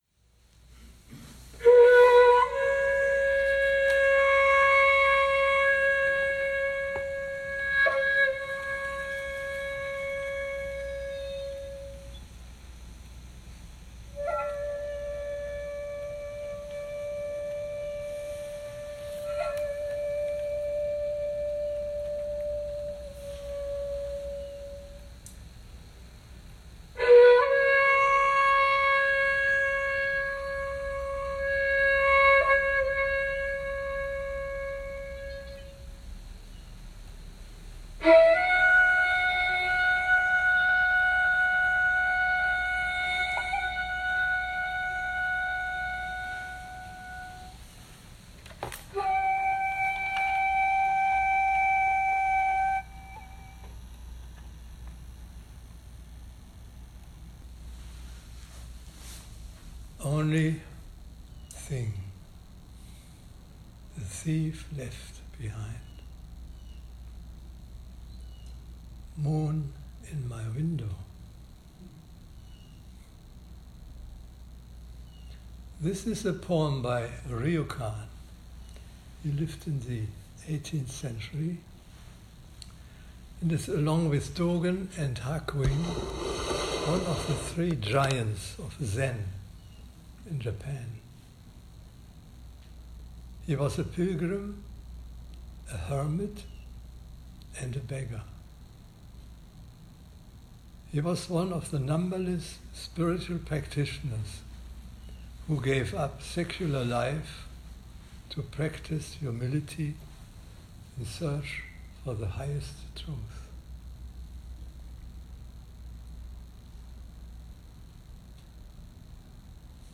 Dharma Talk
July 14th 2018 Southern Palm Zen Group